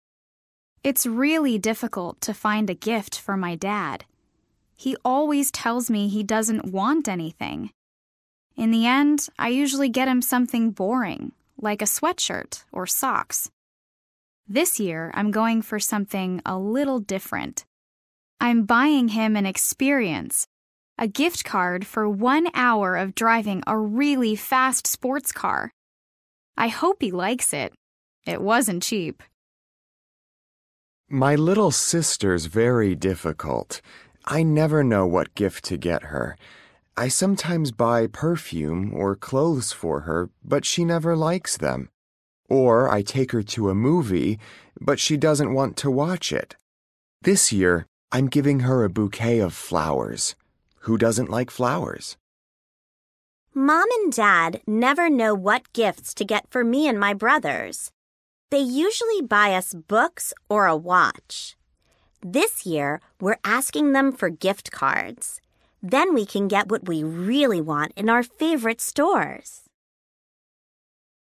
Listen to three people talking about gifts.